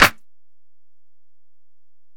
Snare (12).wav